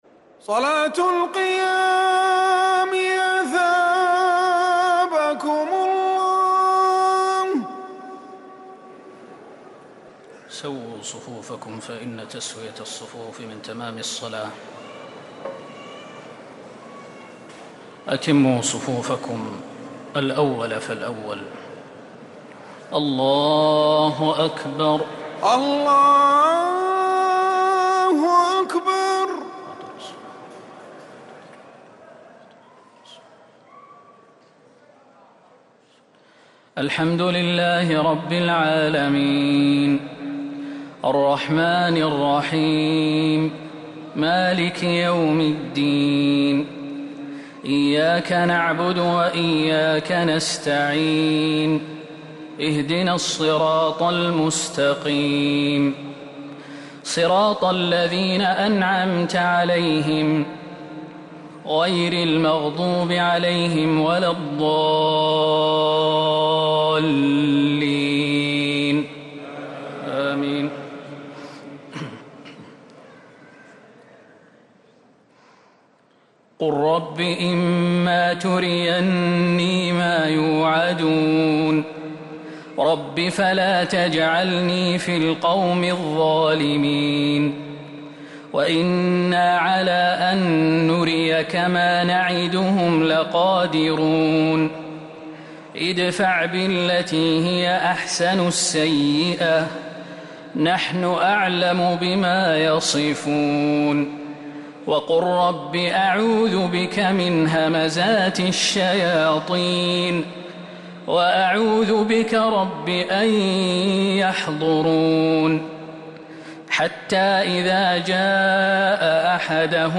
تهجد ليلة 22 رمضان 1444هـ من سورة المؤمنون (93-118) والنور كاملة | Tahajjud prayer | The night of Ramadan 22 1444H | Surah Al-Muminoon and An-Noor > تراويح الحرم النبوي عام 1444 🕌 > التراويح - تلاوات الحرمين